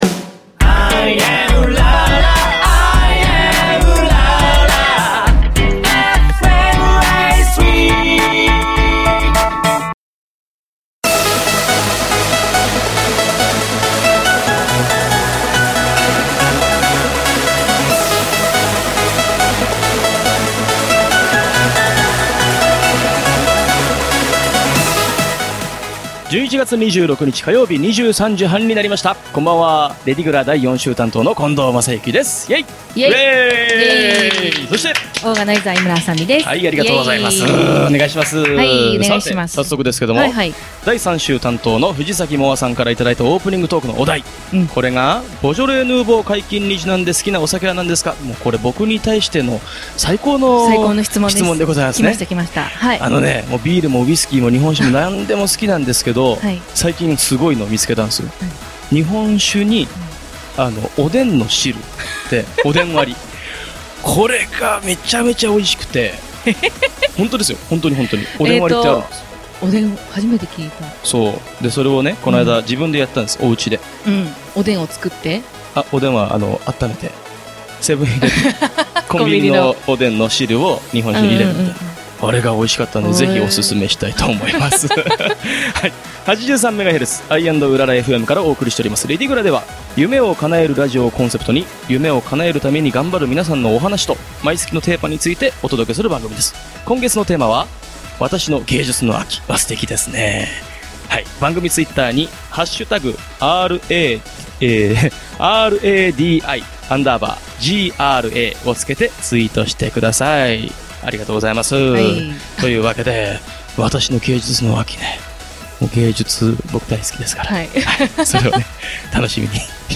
『夢を叶えるラジオ』をコンセプトに、 夢を叶えるため頑張るお話や、毎月変わるテーマについて、週替わりパーソナリティーやゲストと共に、お便りやメッセージなどをお届けする番組。
エンディング曲は毎月変わります。